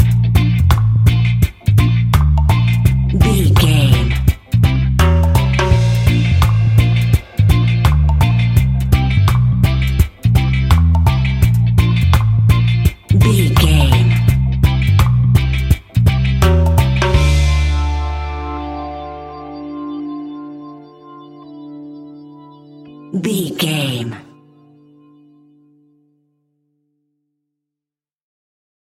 Classic reggae music with that skank bounce reggae feeling.
Aeolian/Minor
D
laid back
chilled
off beat
drums
skank guitar
hammond organ
transistor guitar
percussion
horns